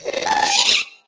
sounds / mob / ghast / charge.ogg
charge.ogg